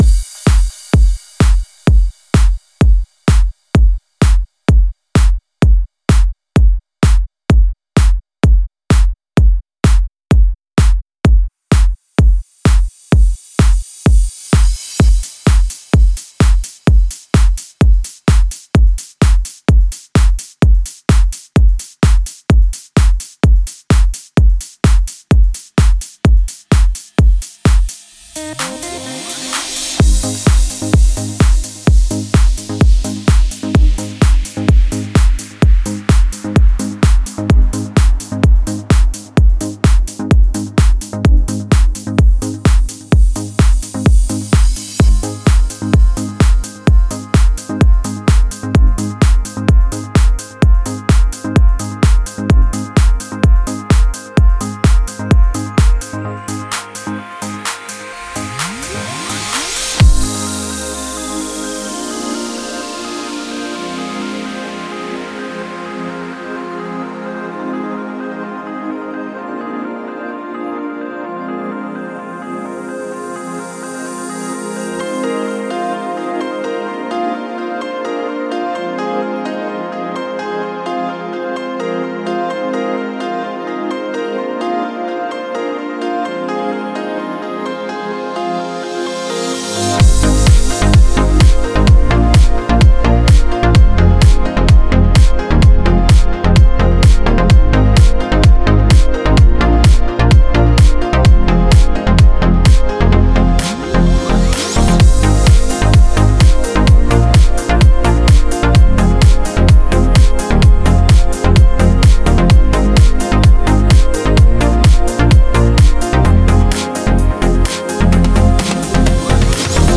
Стиль: Progressive House / Melodic Progressive